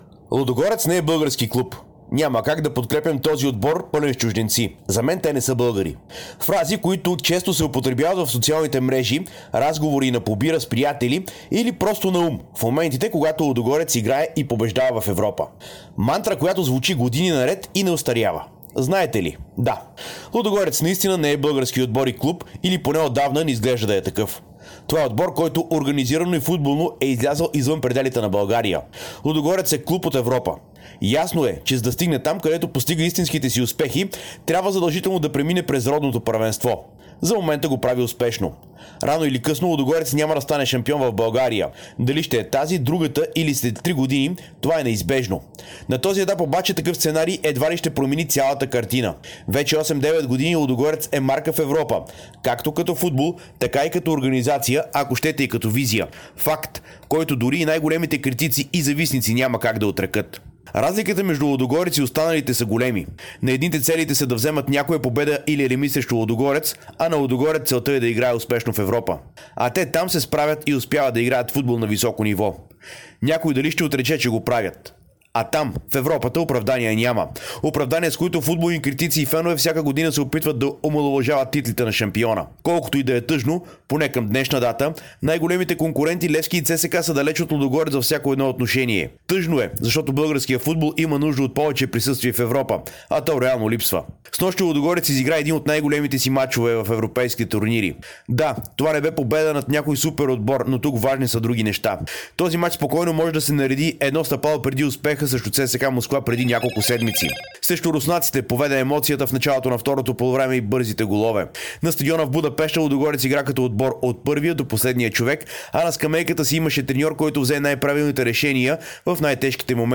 Чуйте коментара